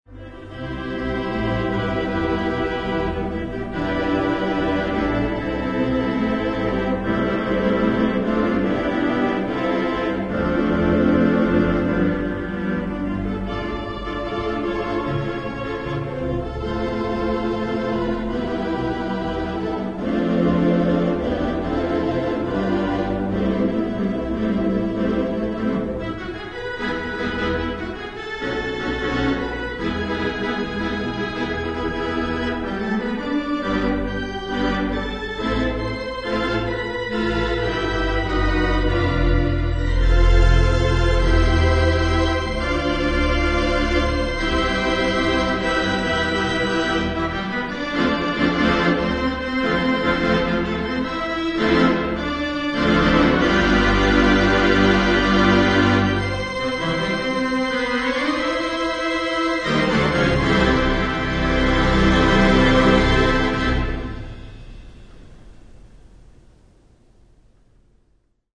Location: Shea's Performing Arts Center, Buffalo, NY
Instrument: 4/28 Wurlitzer OPUS 1206
Notes: This recording was made with a Tascam DA-20 DAT deck during a practice session.